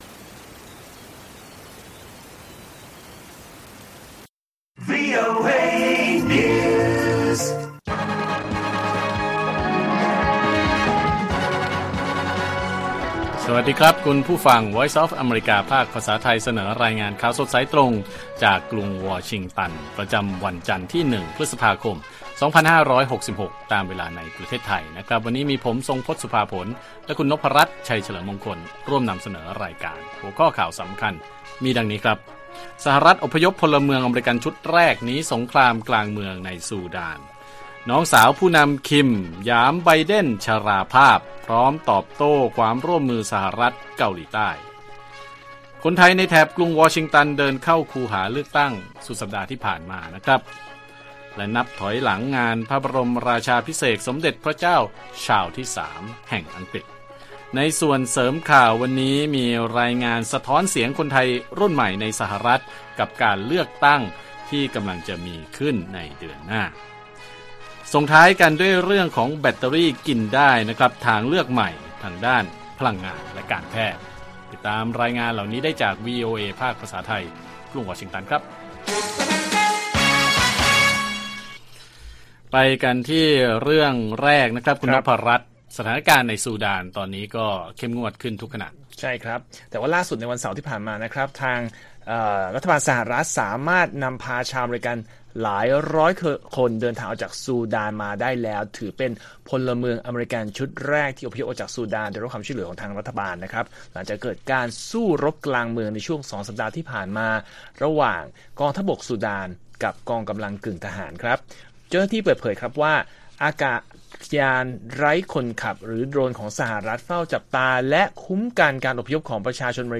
ข่าวสดสายตรงจากวีโอเอไทย 6:30 – 7:00 น. วันที่ 1 พ.ค. 2566